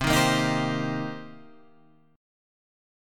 C Minor 7th